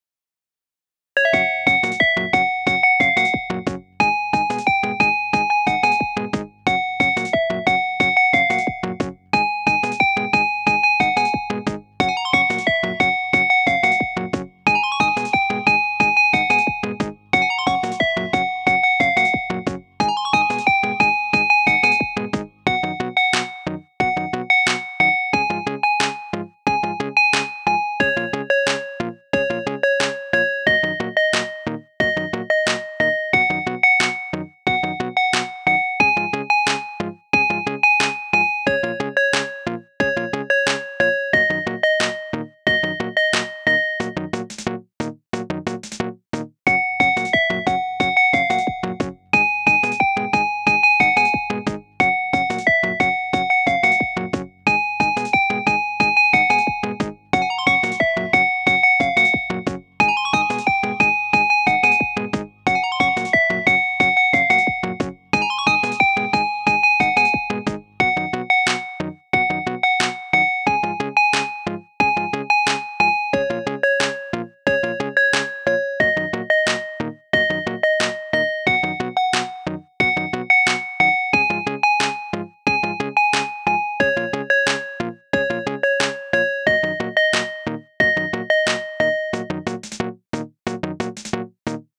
可愛いポップシンセと軽やかさを出してみました！
ループ：◎
BPM：180 キー：F# ジャンル：あかるい、みらい 楽器：シンセサイザー